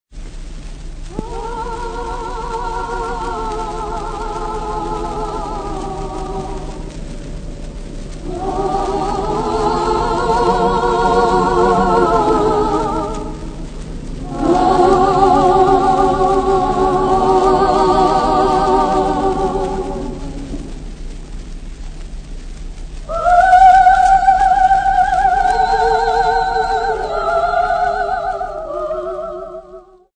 Choir of Saint Mary's Convent School
Popular music--Africa
field recordings
Love song by a choir of 36 girls